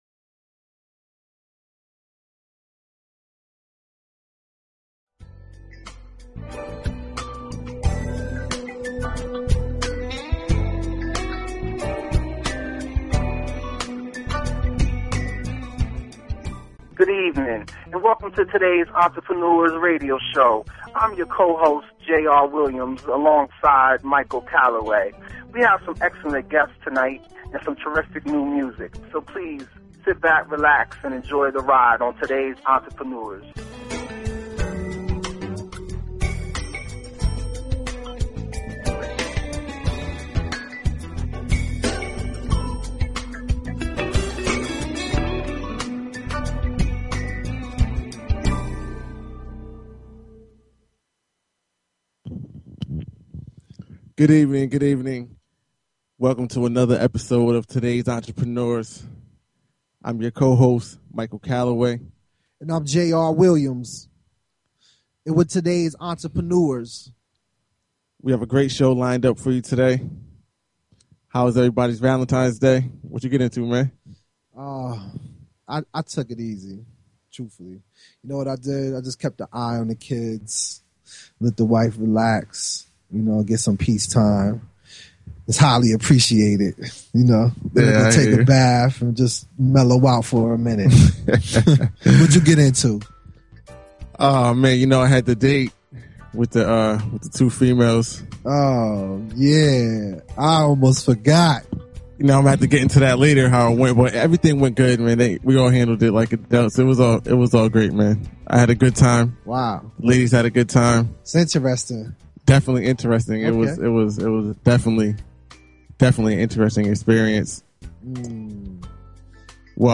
Talk Show Episode, Audio Podcast, Todays_Entrepreneurs and Courtesy of BBS Radio on , show guests , about , categorized as
During each broadcast, there will be in-depth guest interviews discussing the problems and advantages business owners face. Topics will include sales and marketing, branding, interviewing, and much more.
In addition to daily guests, TE spins the hottest indie musical artists and laughs along to the funniest comedians every day.